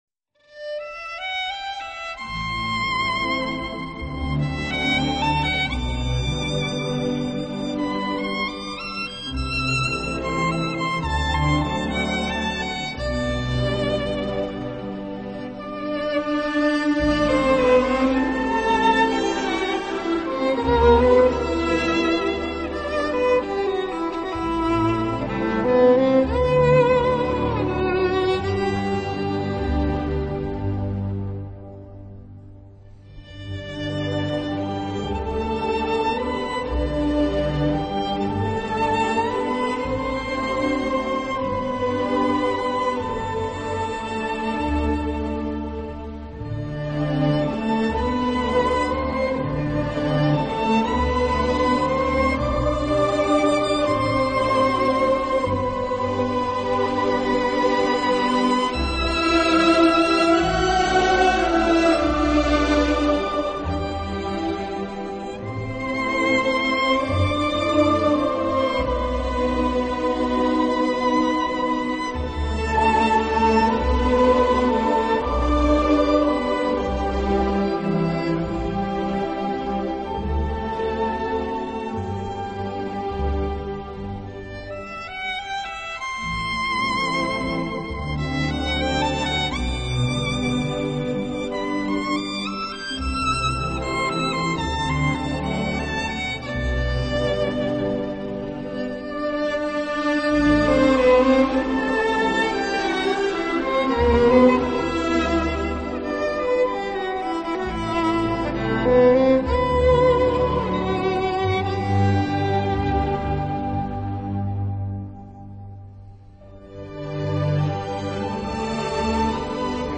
【小提琴专辑】
音乐类型：Classic 古典
音乐风格：Classical,Waltz